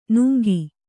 ♪ nuŋgi